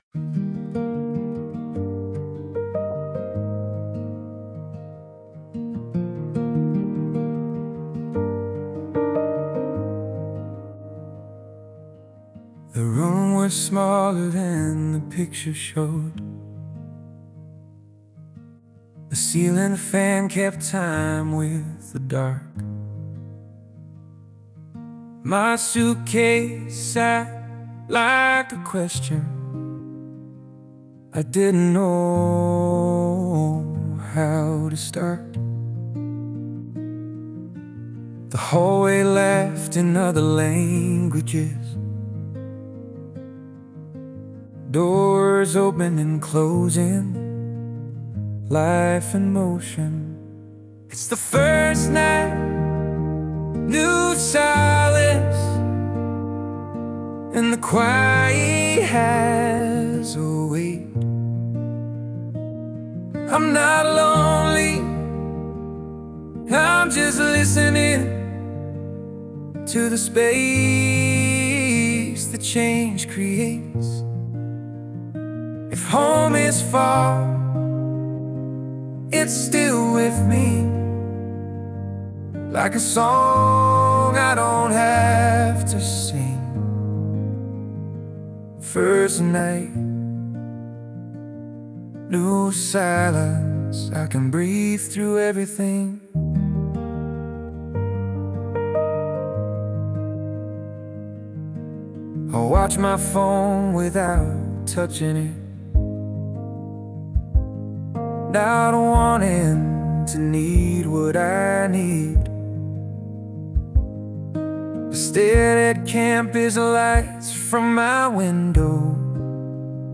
Discover the Heartbeat of Acoustic Storytelling